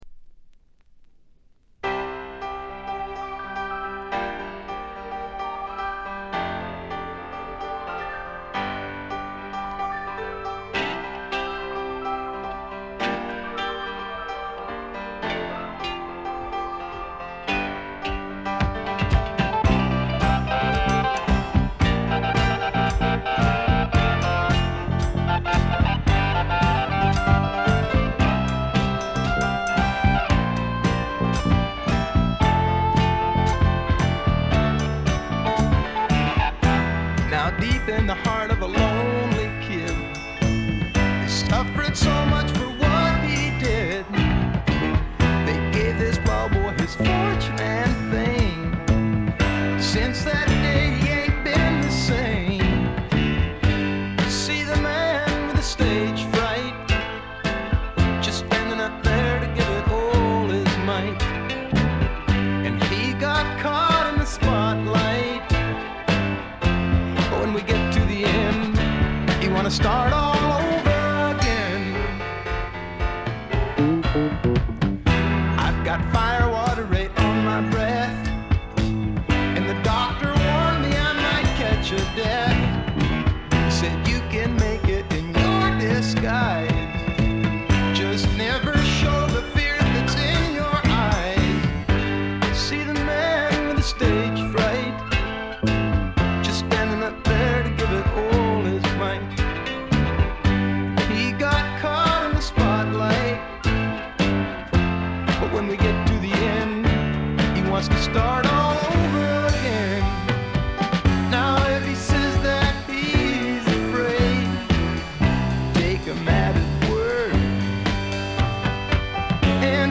ディスク：部分試聴ですがほとんどノイズ感無し。
試聴曲は現品からの取り込み音源です。